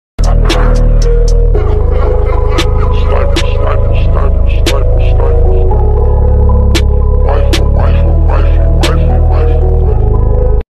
Hahahaha Sound Effects Free Download